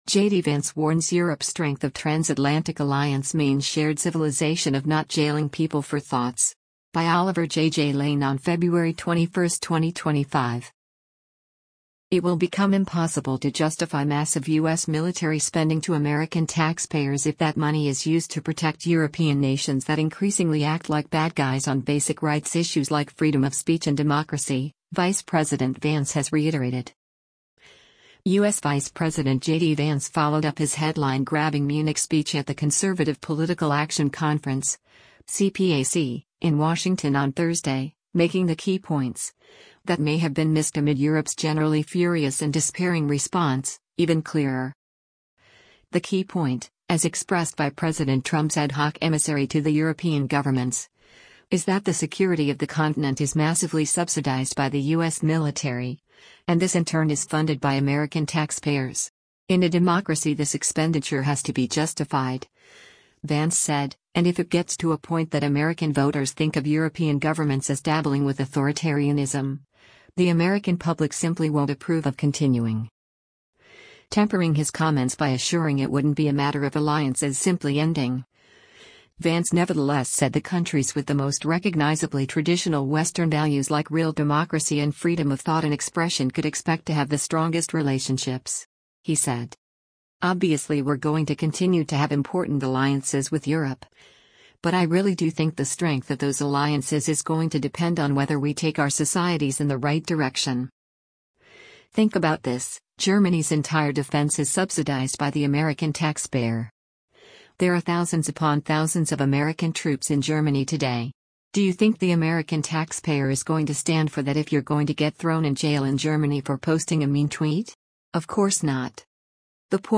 US Vice President JD Vance during the Conservative Political Action Conference (CPAC) in N